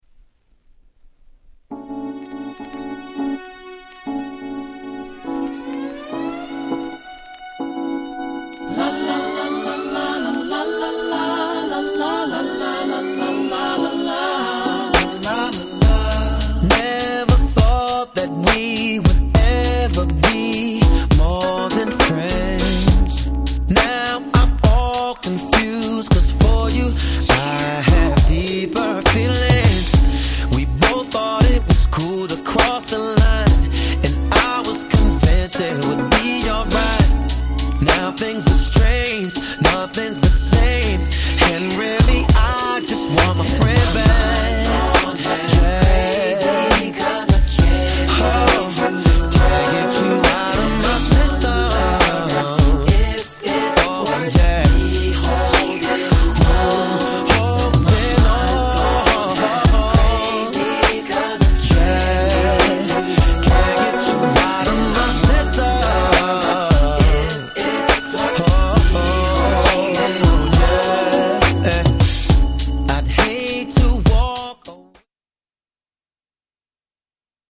SmokeyでChill Outな時間のお供にいかがでしょうか？？